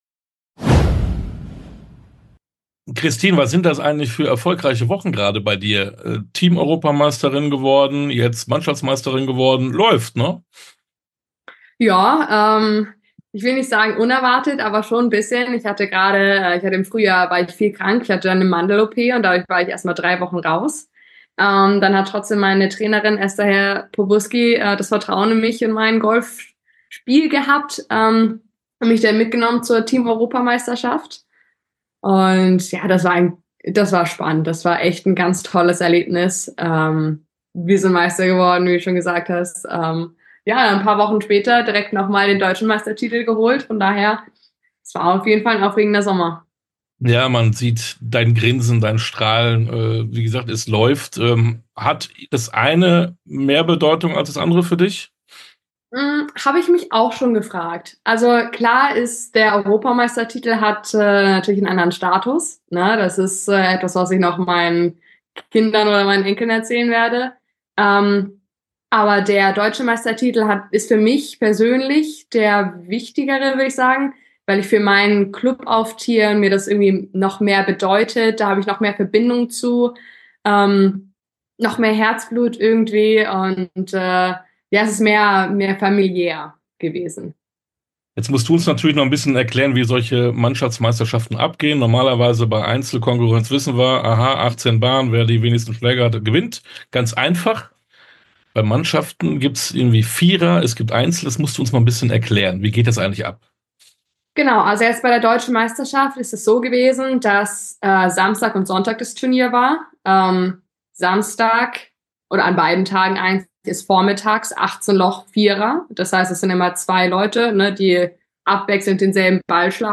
Interview komplett